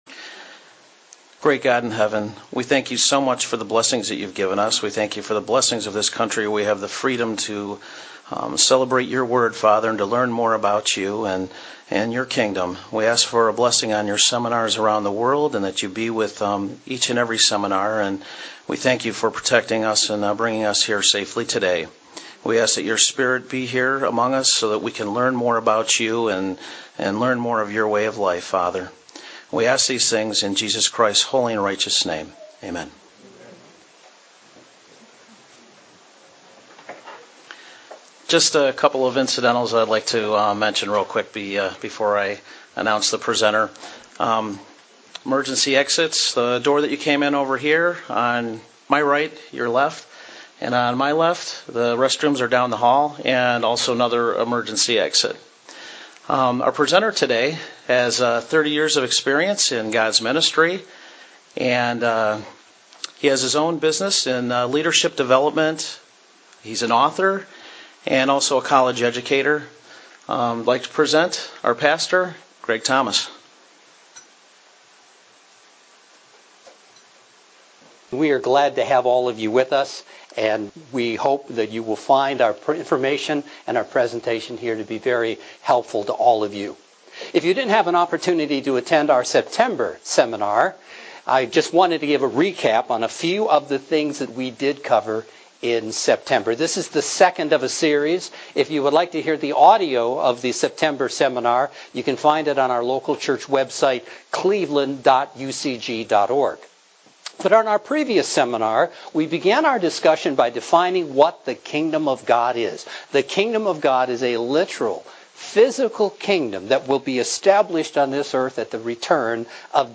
Mankind's problems are a problem of the heart that can only be solved by the return of Jesus Christ. Jesus preached the good news of the coming Kingdom of God. Learn about God's purpose and plan in this Kingdom of God seminar.